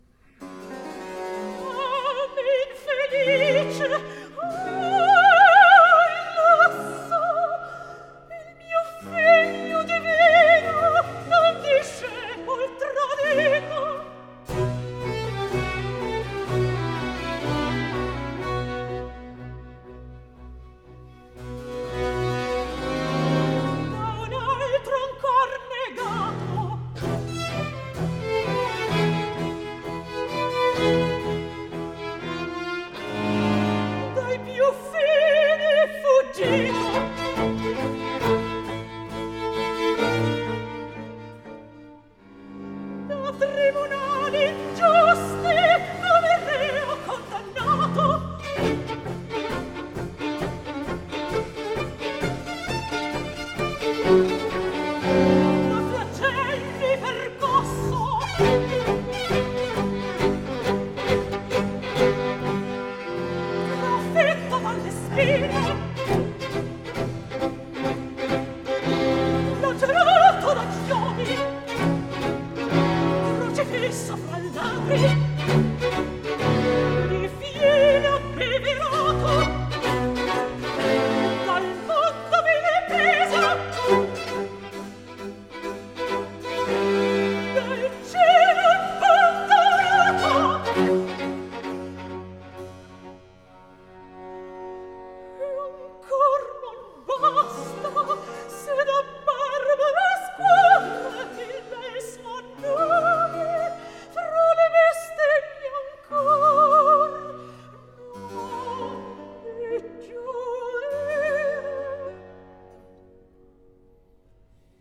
Знаменитая кавантина с речитативом